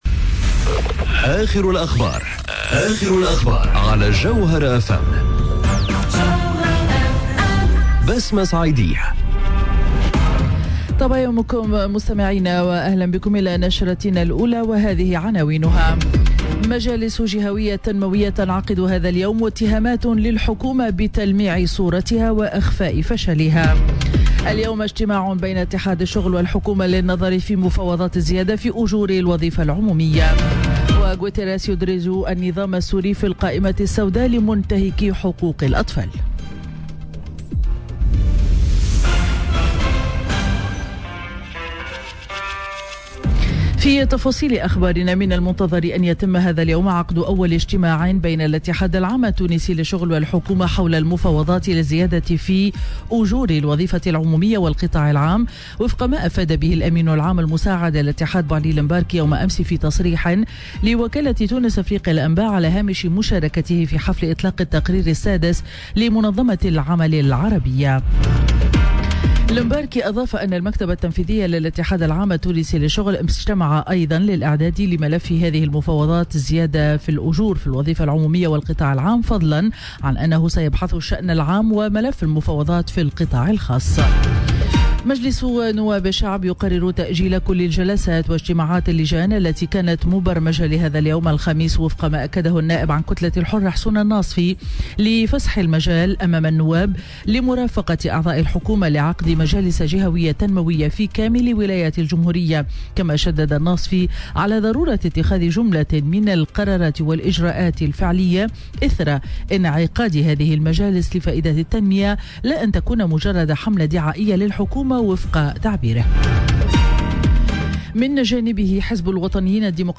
نشرة أخبار السابعة صباحا ليوم الخميس 28 جوان 2018